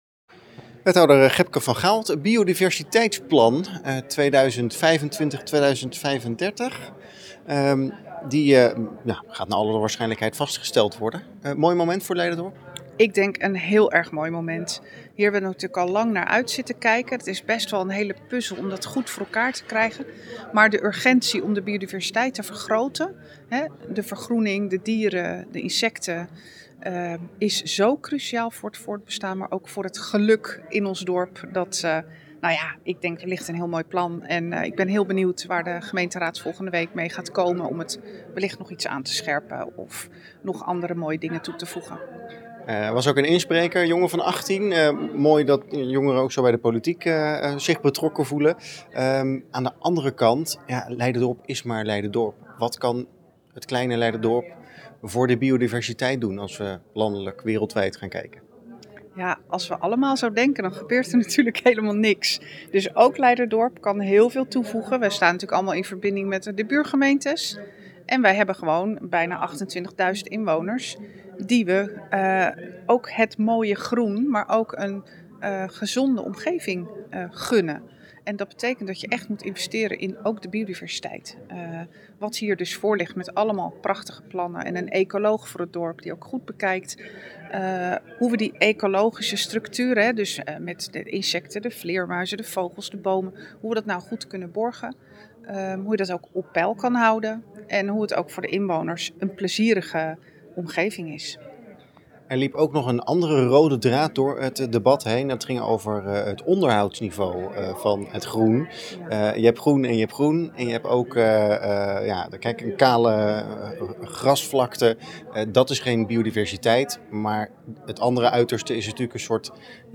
Wethouder Gebke van Gaal over het biodiversiteitsplan.
Wethouder-van-Gaal-Biodiversiteit.mp3